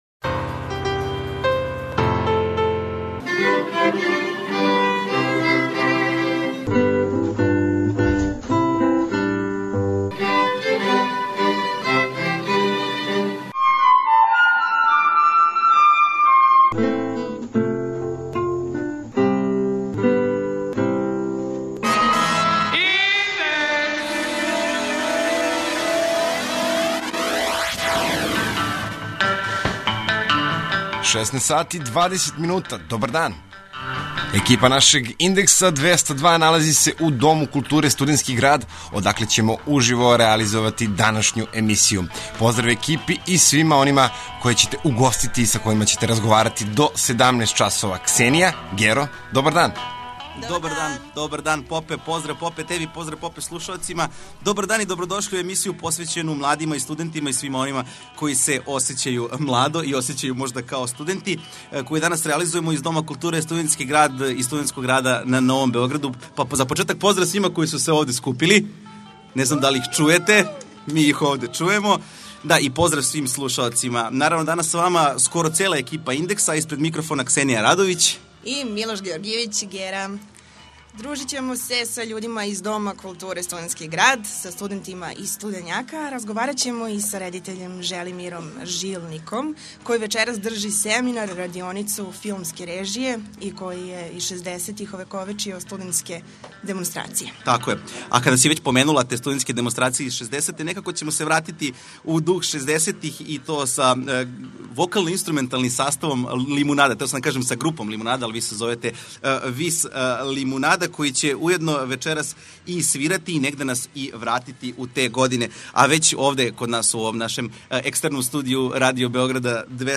Индекс, најслушанија и најстарија студентска емисија Радио Београда 202, данас ће бити реализована из Дома културе ''Студентски град''.
Ово ће бити несвакидашња прилика да уживо пренесемо атмосферу из једног од највећих студентских пребивалишта на Балкану, у трајању целе радијске емисије. Угостићемо занимљиве становнике ''Студењака'' и ексклузивног госта Желимира Жилника , који ће држати предавања о филму.